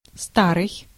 Ääntäminen
IPA : /ænˈtiːk/